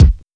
• Solid Kick One Shot C Key 612.wav
Royality free kick drum sound tuned to the C note. Loudest frequency: 220Hz
solid-kick-one-shot-c-key-612-ynS.wav